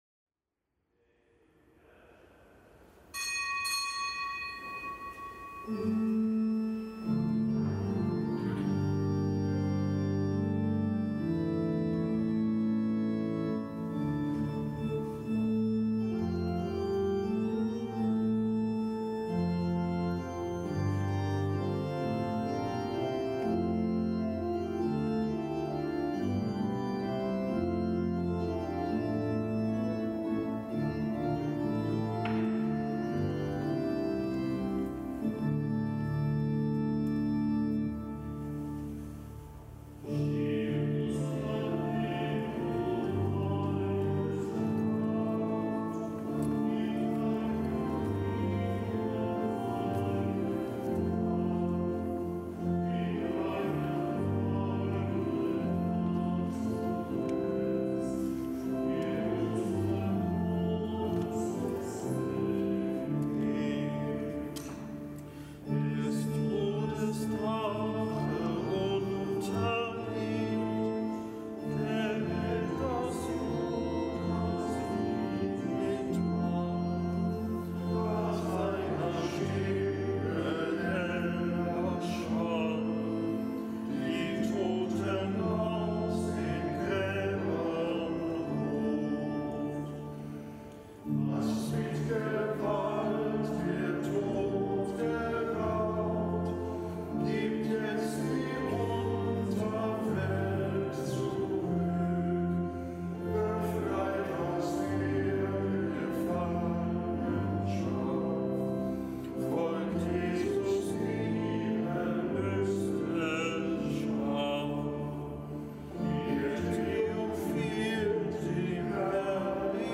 Kapitelsmesse aus dem Kölner Dom am Mittwoch der dritten Osterwoche.